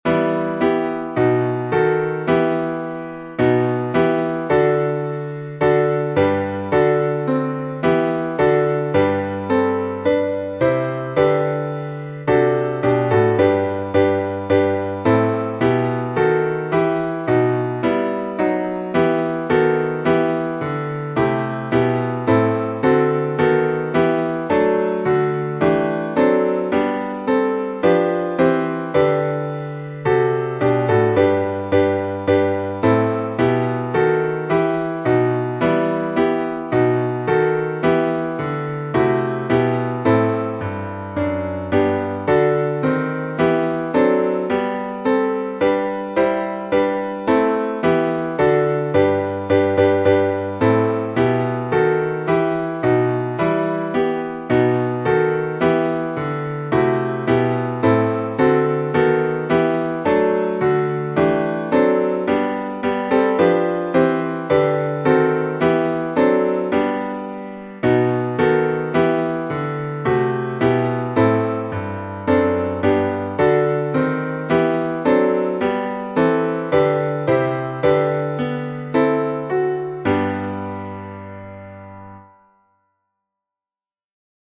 Галицко-волынский напев